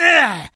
troll_archer_damage.wav